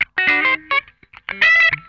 CRUNCHWAH 10.wav